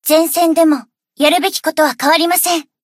贡献 ） 分类:蔚蓝档案语音 协议:Copyright 您不可以覆盖此文件。
BA_V_Chinatsu_Hotspring_Battle_In_1.ogg